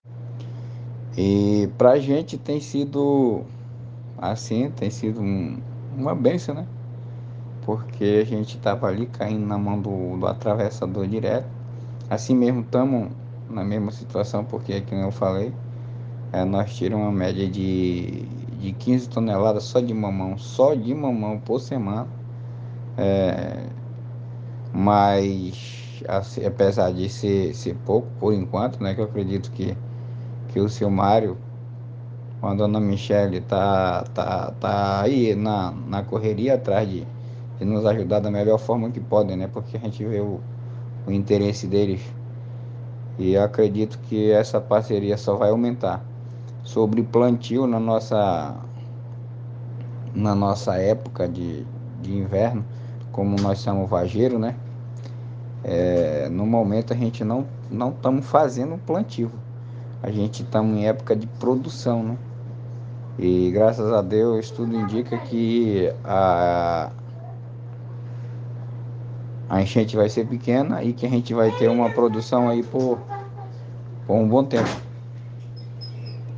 fala do produtor